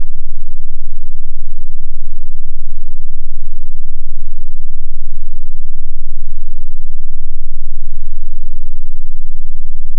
AudioCheck Tone Tests
Low Frequency Tones